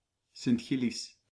) or Sint-Gillis (Dutch, pronounced [sɪntˈxɪlɪs]
Nl-Sint-Gillis.ogg.mp3